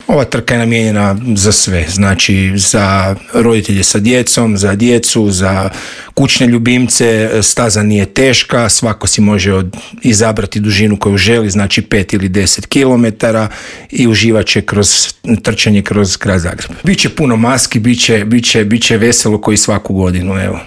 Gost u Intervjuu Media servisa